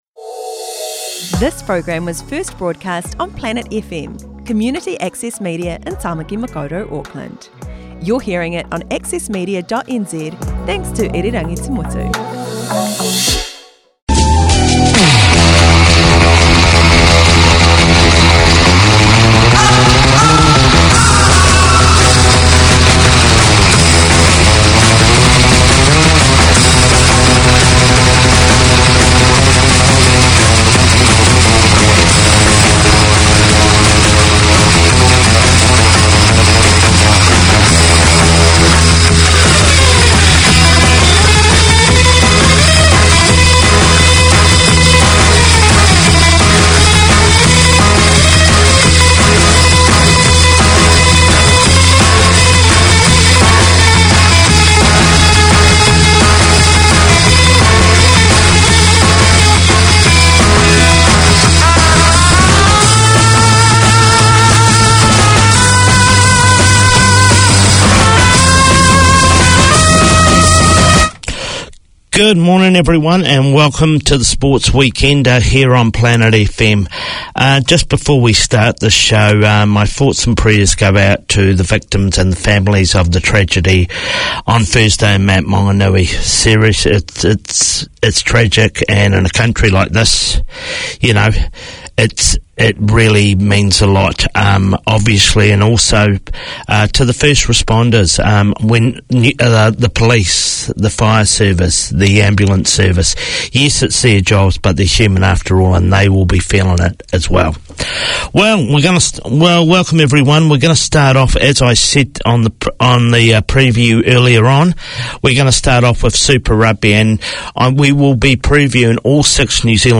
From local legends in grassroots to national level names, the Sports Weekender features interviews with experts and fanatics alike